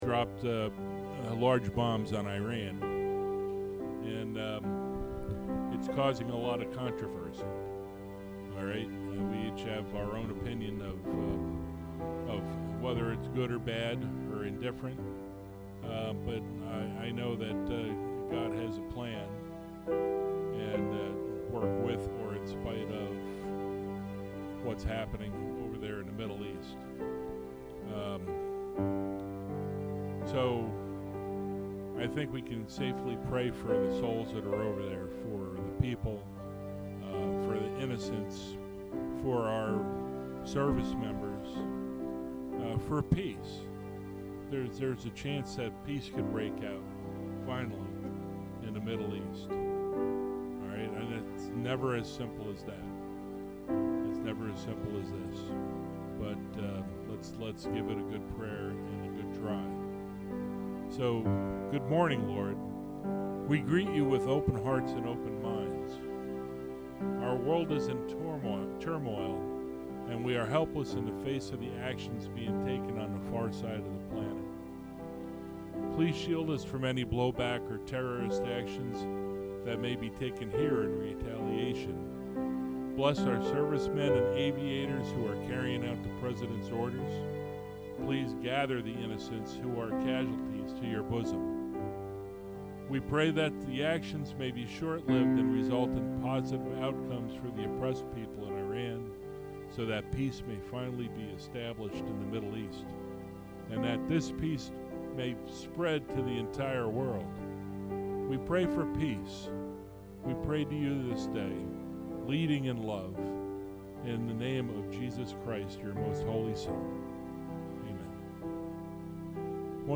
Podcast (sermons): Play in new window | Download